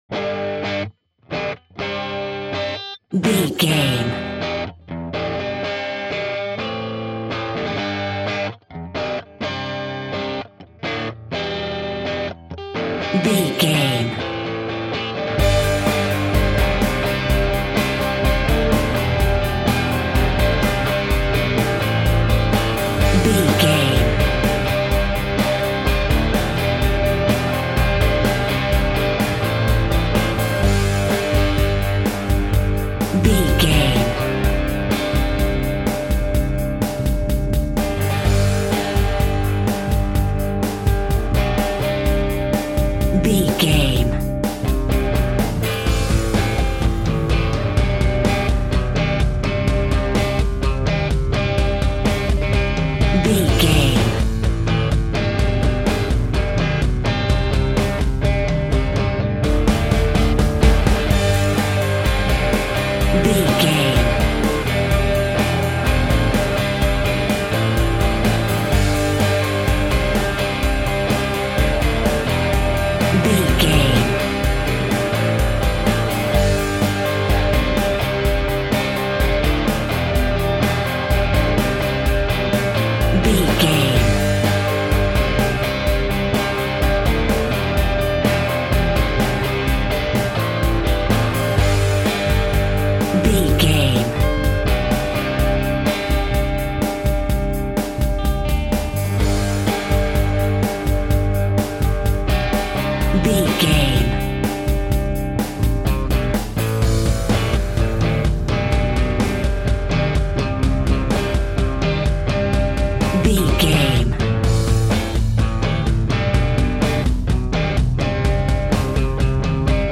Classic Country Pop.
Fast paced
Ionian/Major
Fast
indie rock
drums
bass guitar
electric guitar
piano
hammond organ